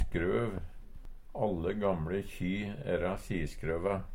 skrøv - Numedalsmål (en-US)